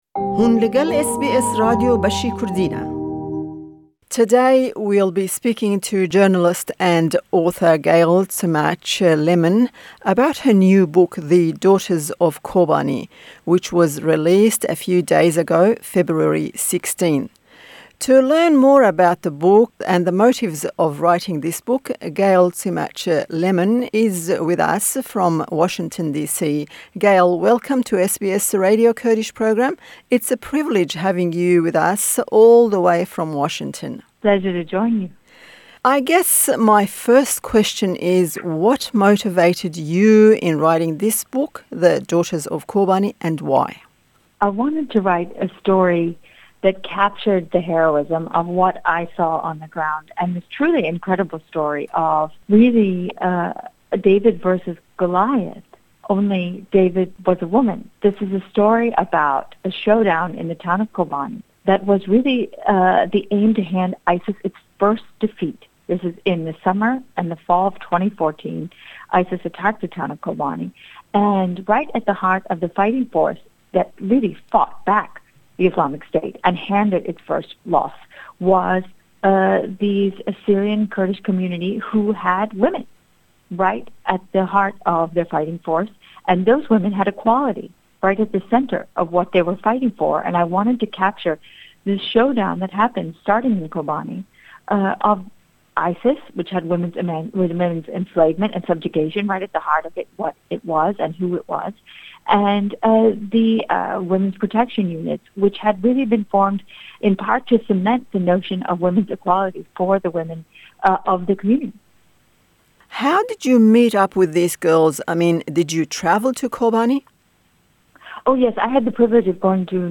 Me derbarê derketina pirtûka bi navê Keçên Kobanî – the daughters of Kobani hevpeyvîneke bi nivîskara pirtûkê Gayle Tzemach lemmon pêk anî.